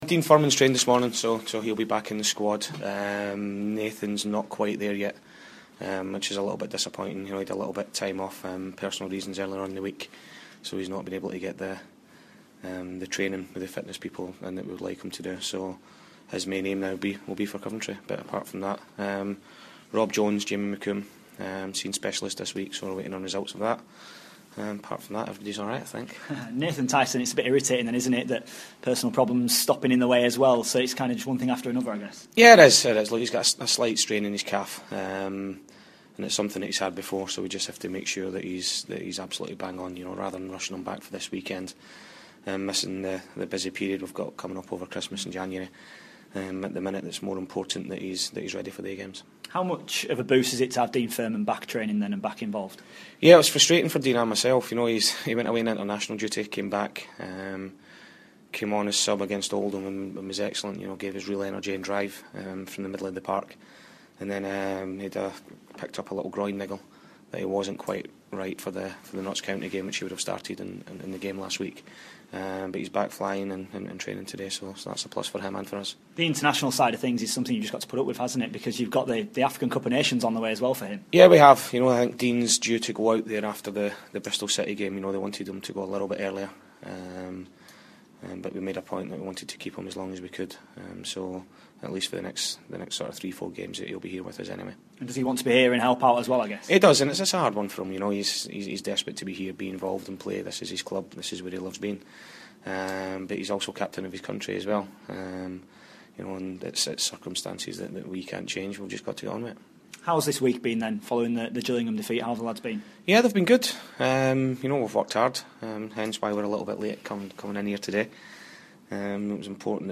INTERVIEW: Doncaster Rovers manager Paul Dickov pre Swindon